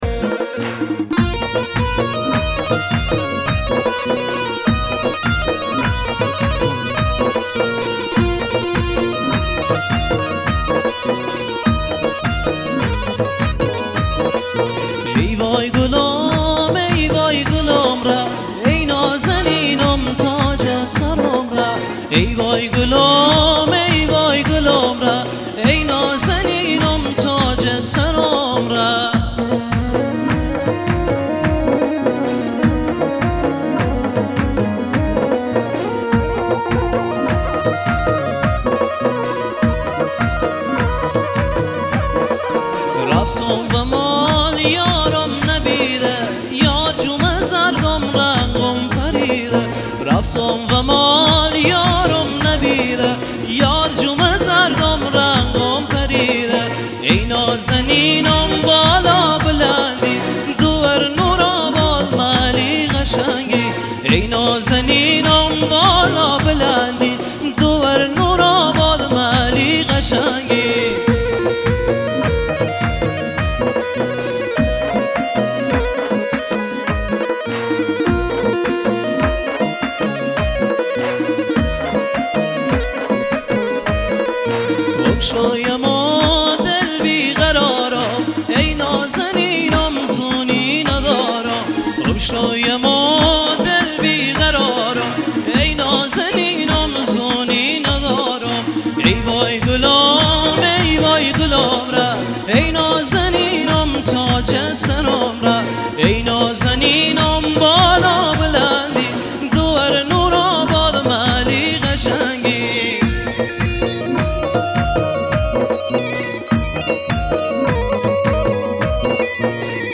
اهنگ بندری